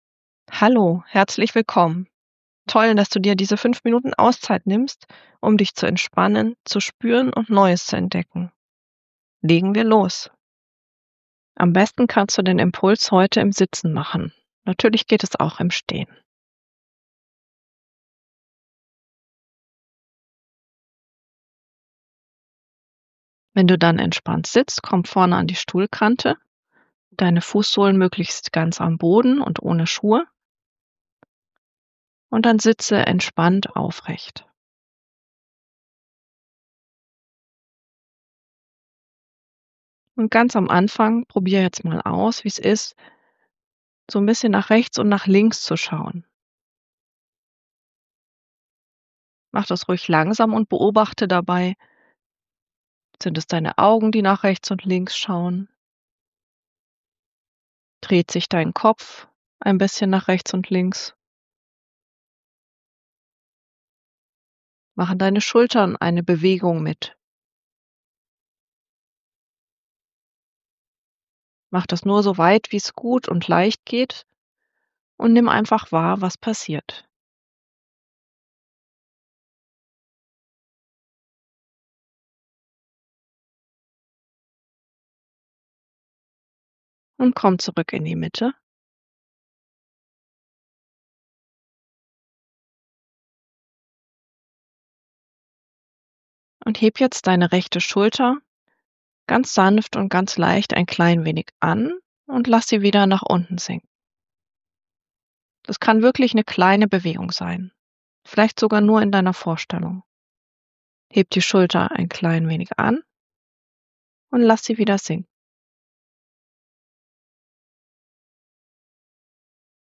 Hier geht es zum 5-Minuten-Entspannung-Audio: